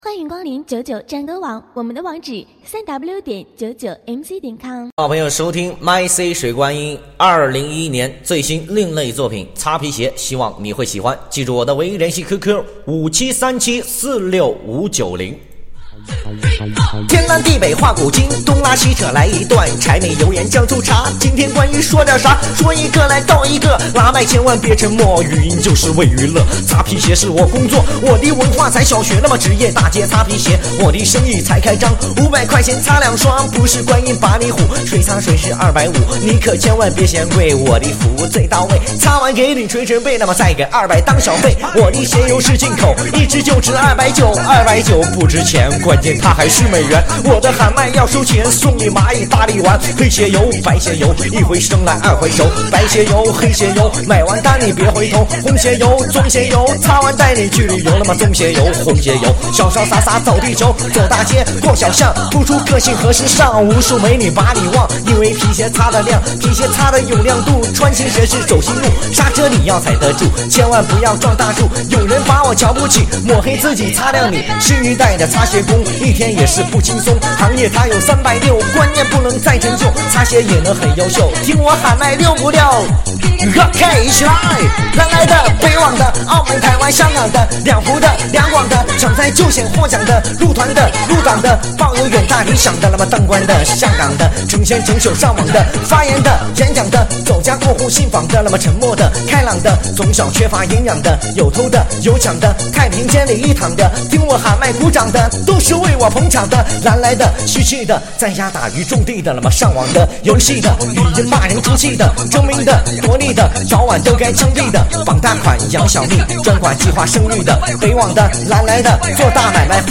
Mc喊麦